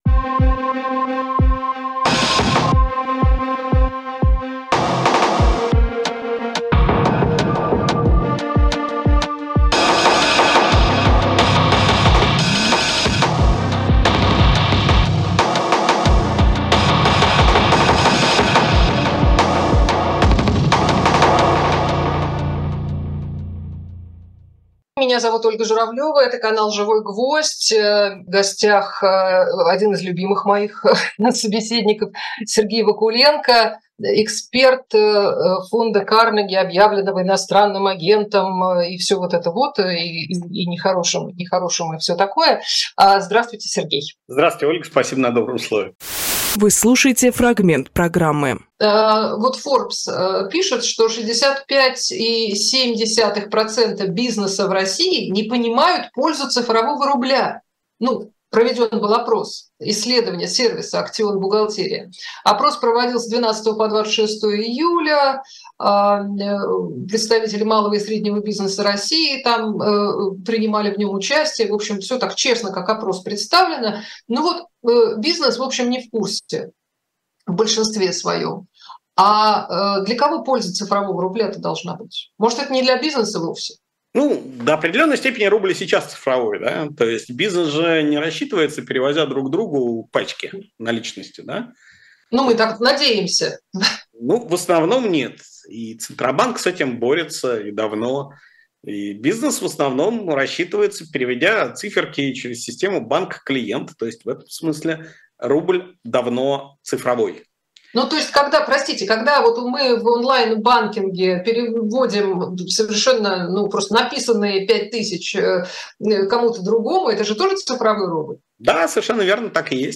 Фрагмент эфира от 28.07